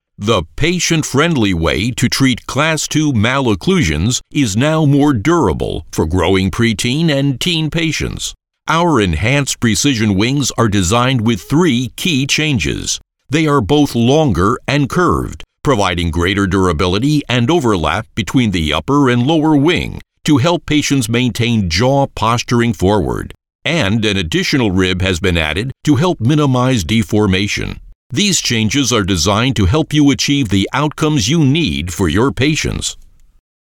Engels (Amerikaans)
Commercieel, Diep, Natuurlijk, Opvallend, Vriendelijk
Explainer